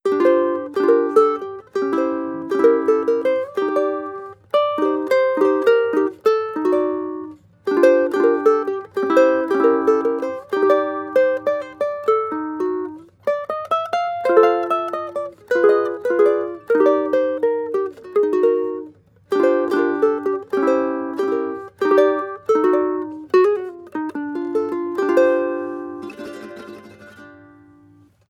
• guitarrico jazz sequence.wav
guitarrico_jazz_sequence_hfp.wav